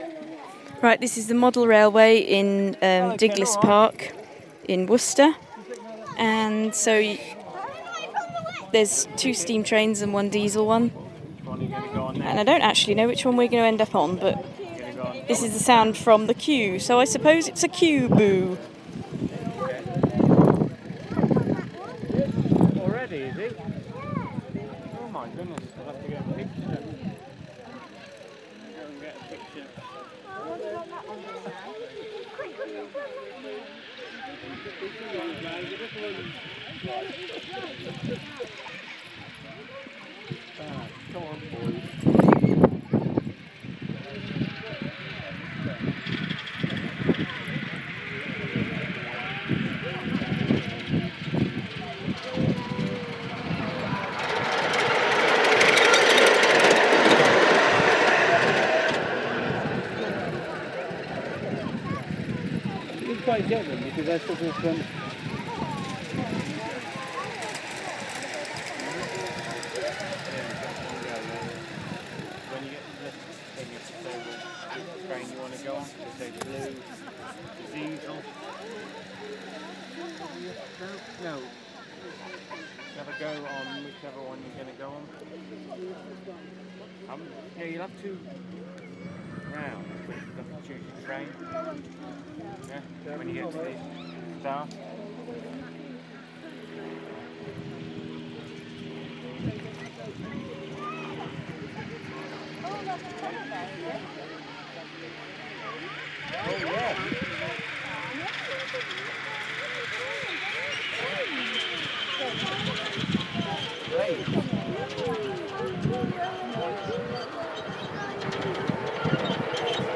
Model railway sounds while we wait.